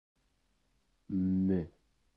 Мм_–_ukrainian.ogg.mp3